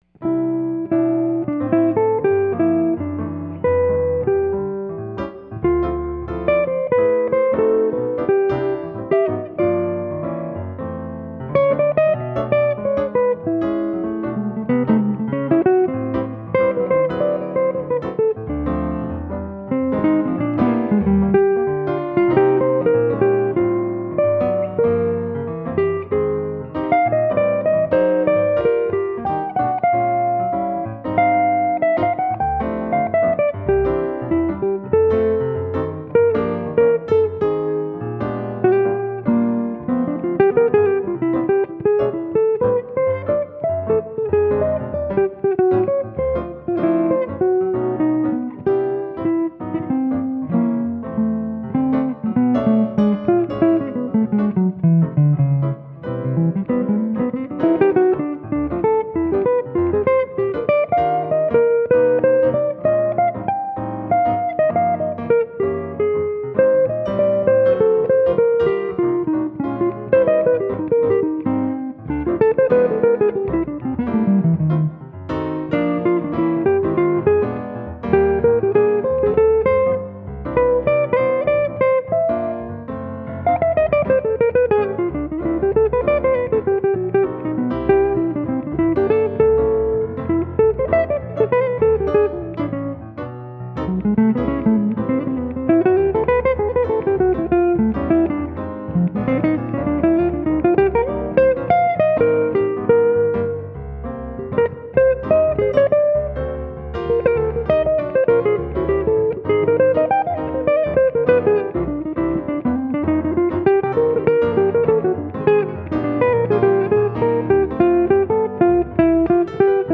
Solo’s and Duo’s
Jazz Guitarist